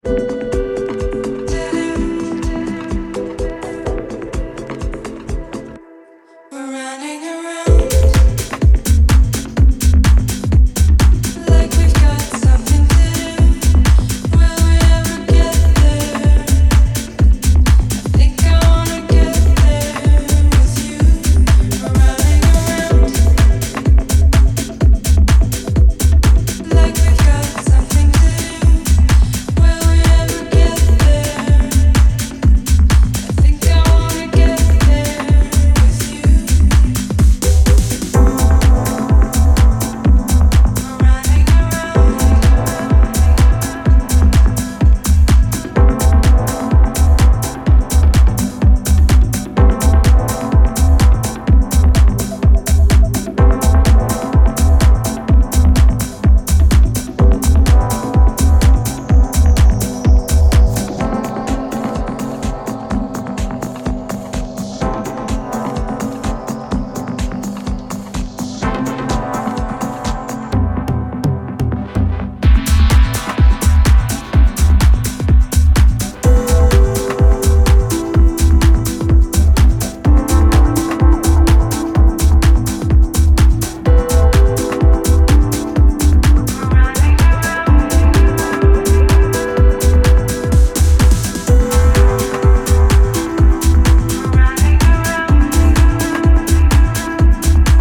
Romantic, ecstatic, and full of <3